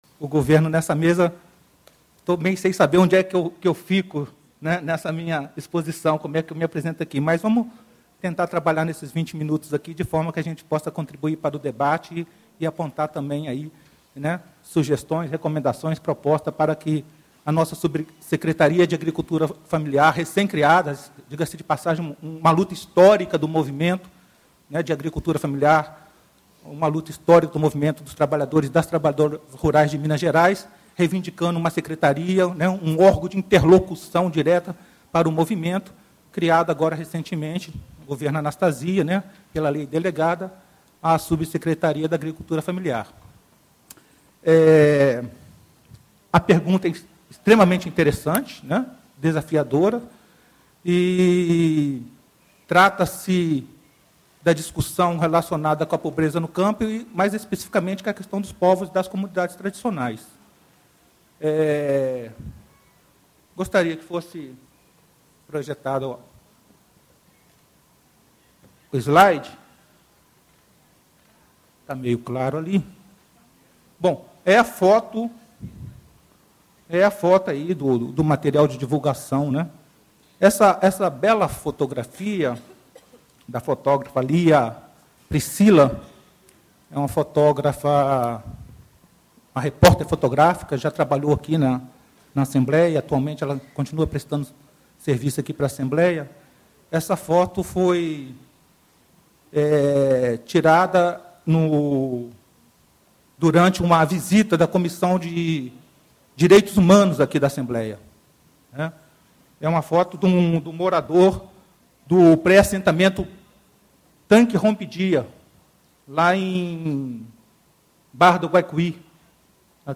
Ciclo de Debates Estratégias para Superação da Pobreza - Palestra: Pobreza no campo e as comunidades tradicionais: como enfrentar a pobreza nas áreas rurais de Minas Gerais - Assembleia Legislativa de Minas Gerais
Edmar Gadelha, Subsecretário de Agricultura Familiar da Secretaria de Estado de Agricultura, Pecuária e Abastecimento de Minas Gerais